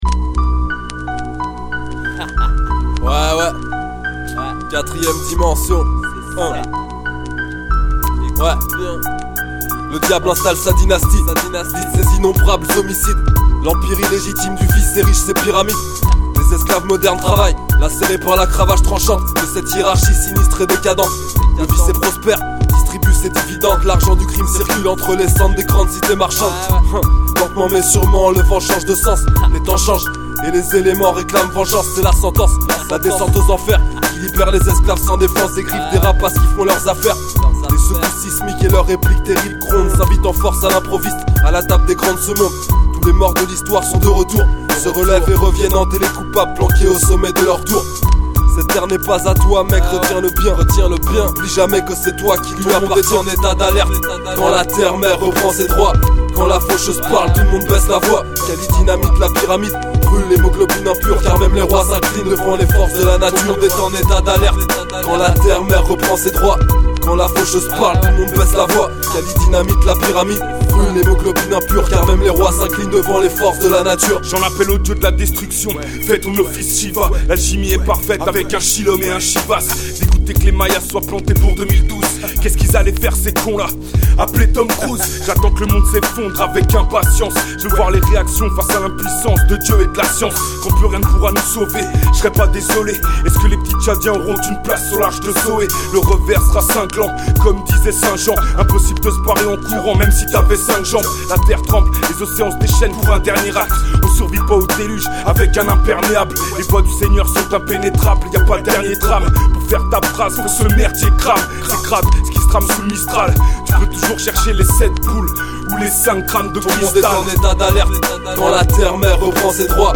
mc